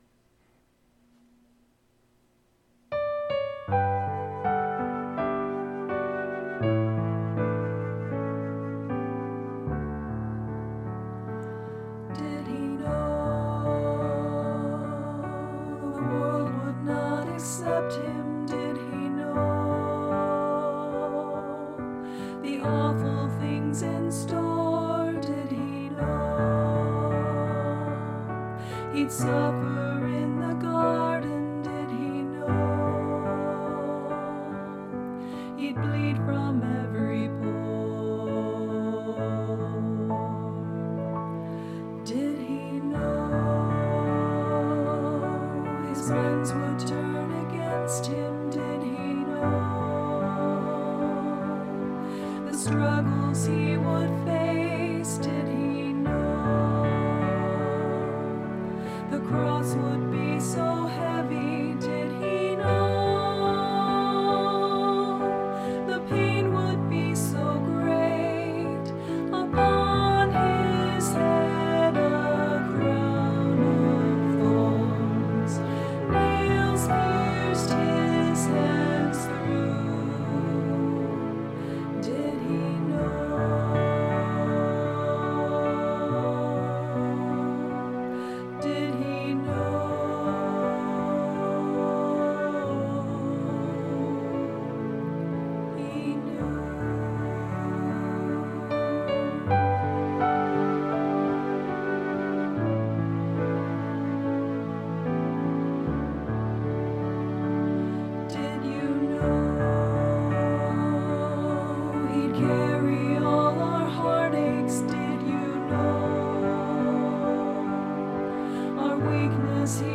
Medium Voice/Low Voice